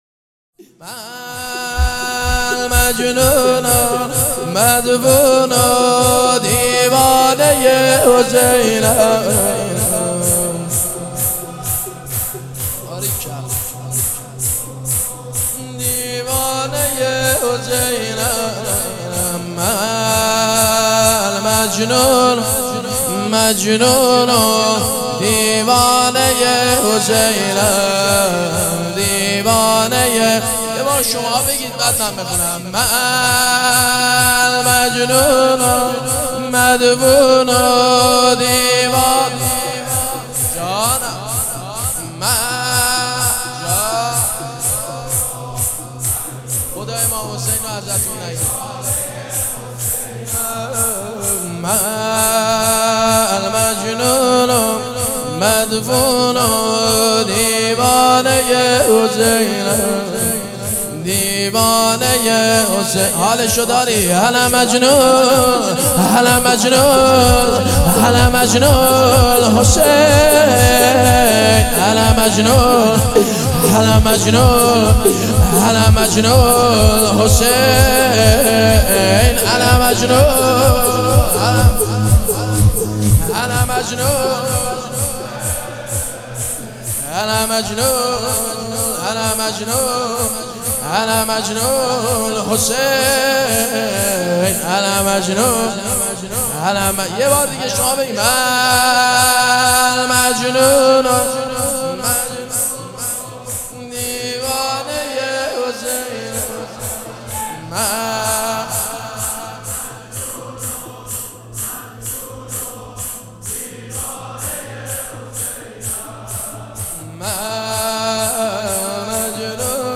نوحه جدید